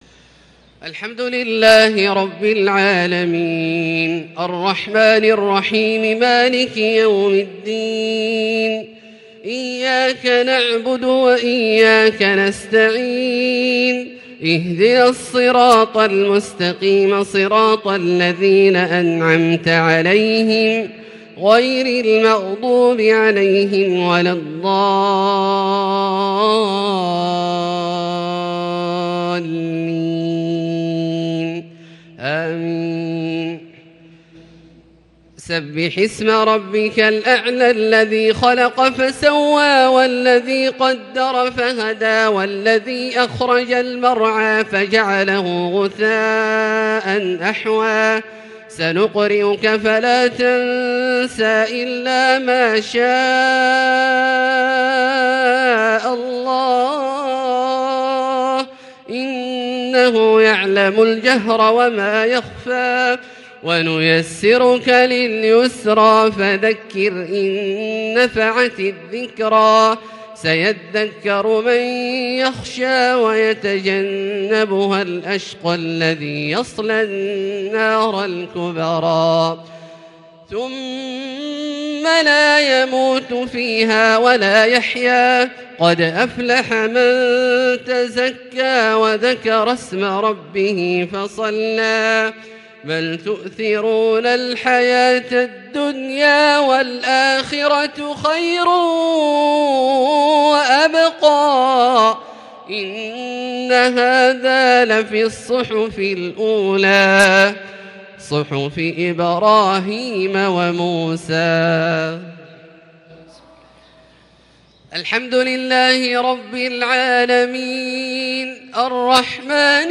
تلاوة لا توصف لسورتي الأعلى والغاشية | صلاة الجمعة 18 رمضان 1442هـ. > ١٤٤٢ هـ > الفروض - تلاوات عبدالله الجهني